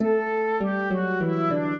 flute-harp
minuet6-3.wav